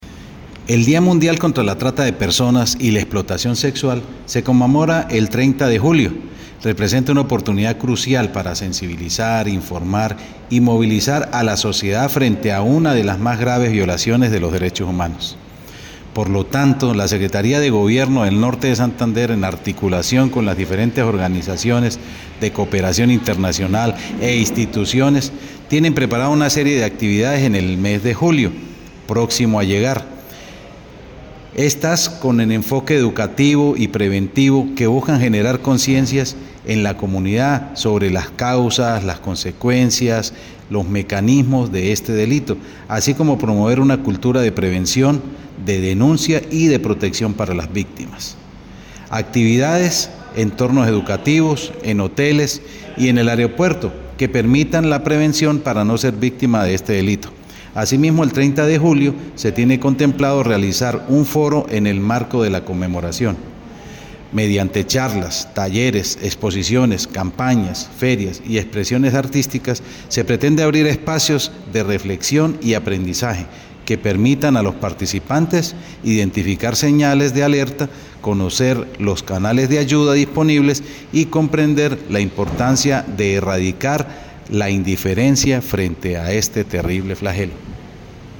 Audio-de-Johnny-Penaranda-secretario-de-Gobierno.mp3